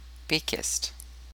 The party traditionally has support from the labour movement; however, unlike most other social democratic parties, its ties with organized labour are informal.[12] Members and supporters of the PQ are nicknamed péquistes (/pˈkst/ pay-KEEST,[13] Quebec French pronunciation: [peˈkɪst]
FR-Péquiste.ogg.mp3